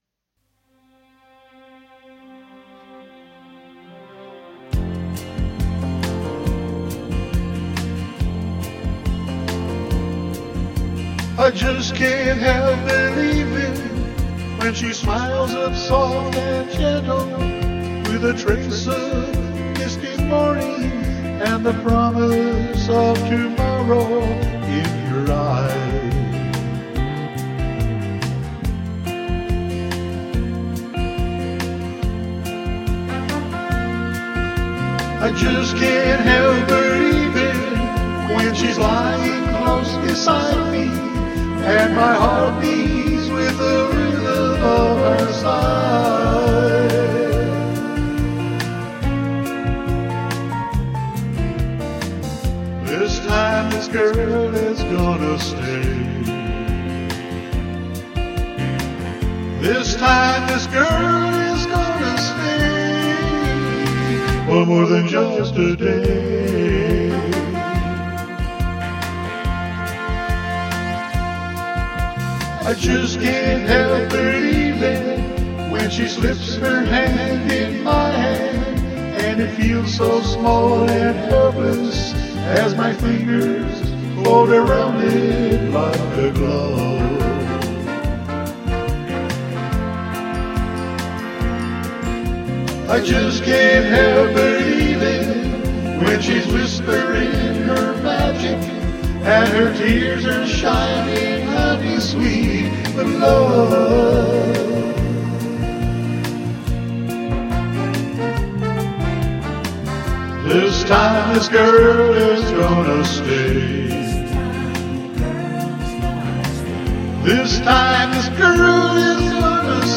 Instrumental Version (With backing vocals)